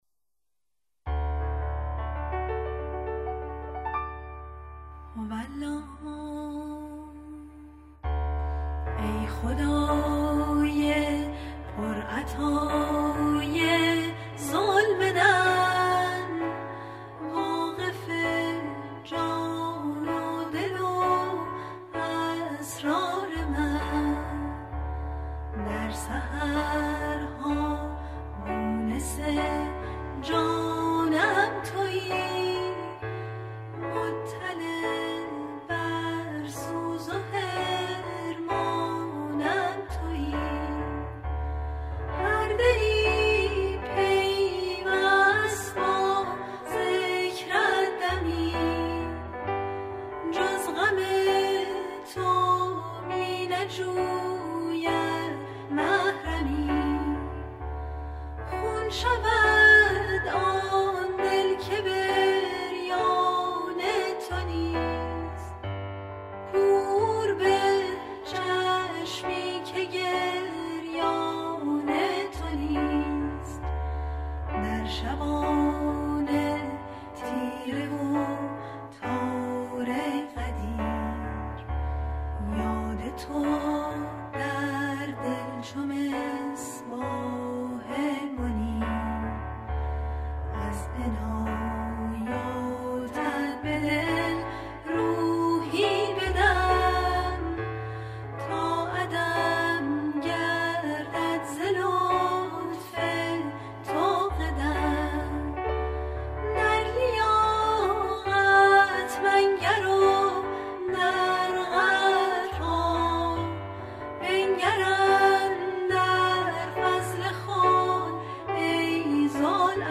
مناجات های صوتی